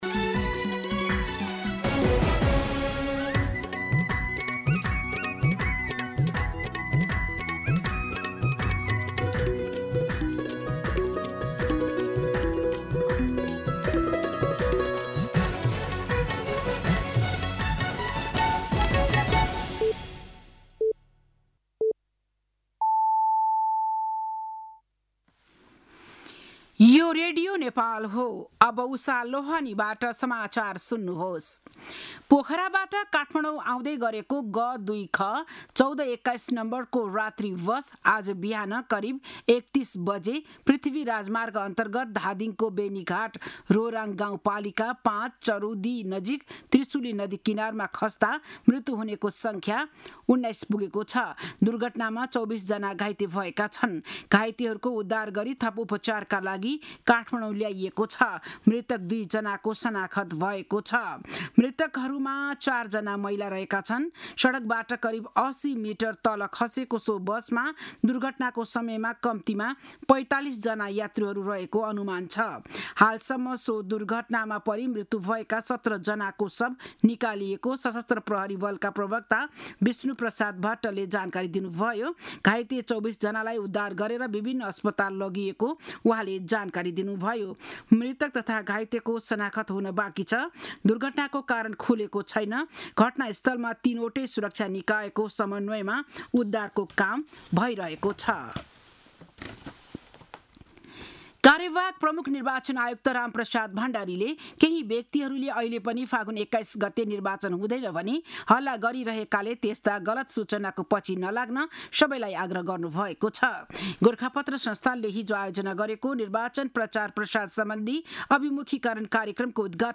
बिहान ११ बजेको नेपाली समाचार : ११ फागुन , २०८२
11am-News-11.mp3